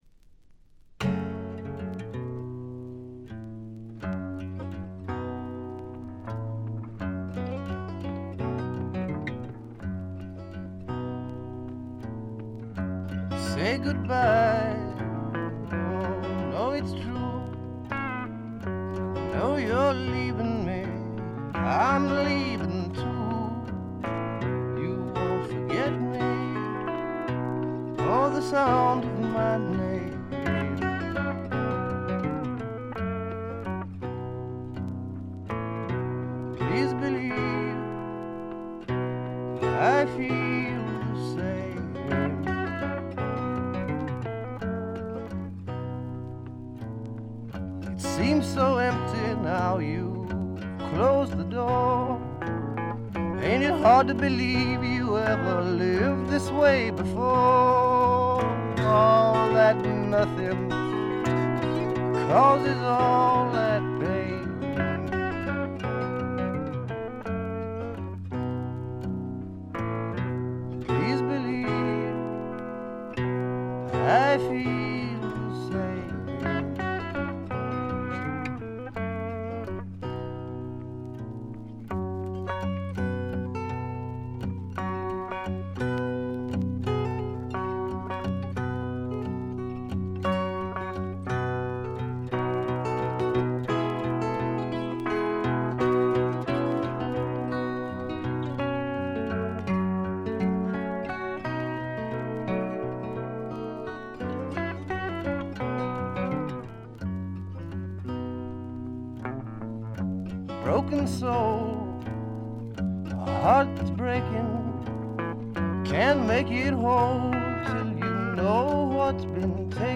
軽微なバックグラウンドノイズ、チリプチ程度。
試聴曲は現品からの取り込み音源です。